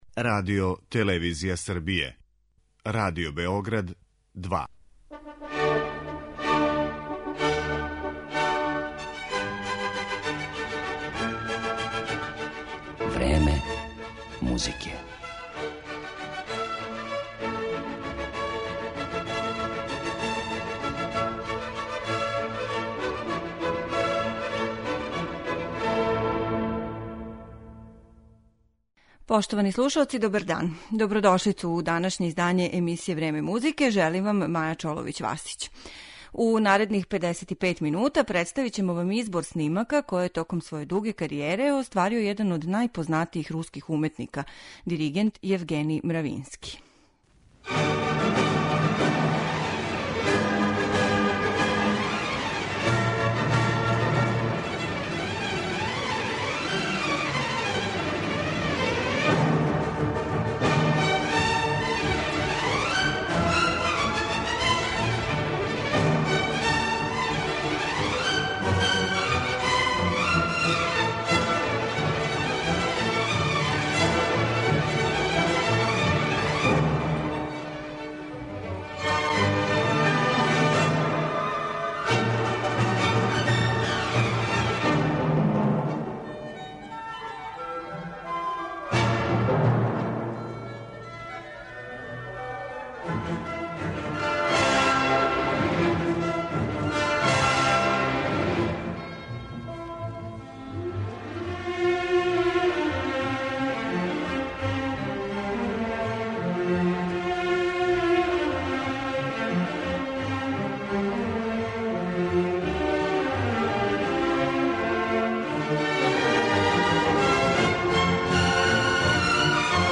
Представићемо диригента Јевгенија Мравинског